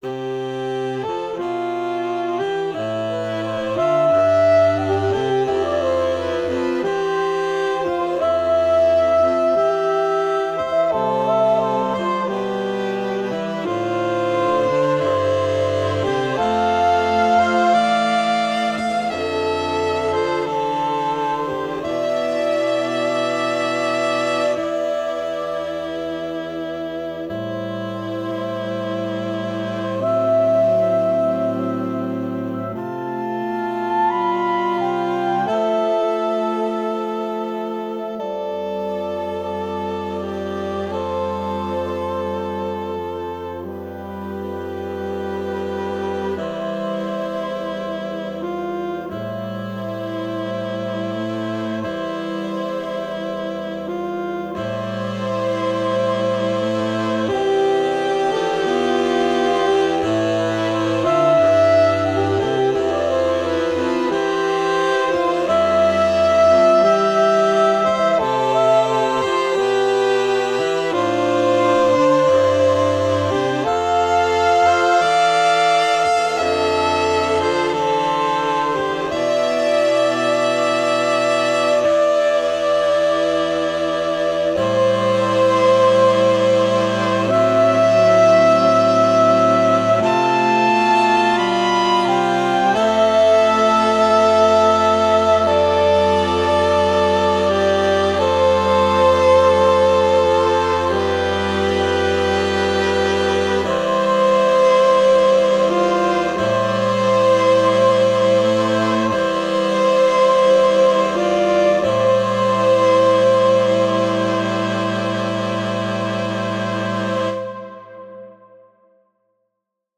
For Saxophones
Honney-Sax.mp3